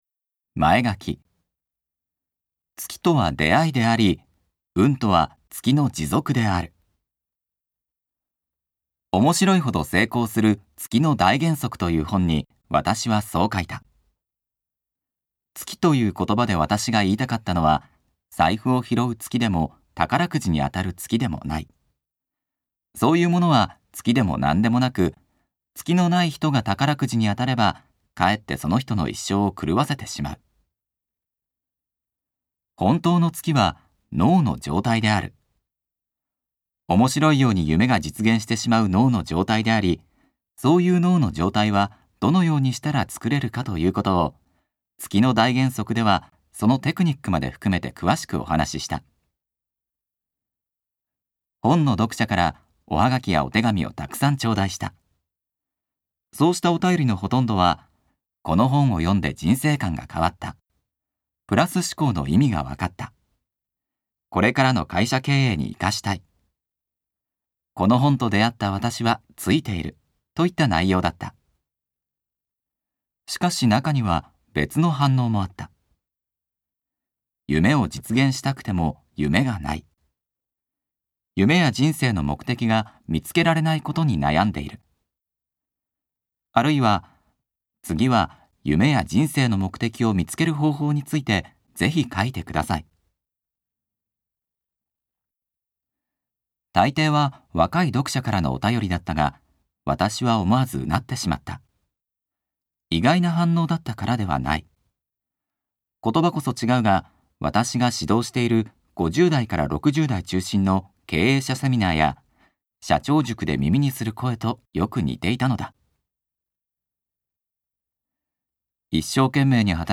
[オーディオブック] 人生の目的が見つかる魔法の杖